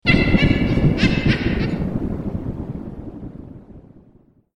Witch Laugh Sound Effect Download: Instant Soundboard Button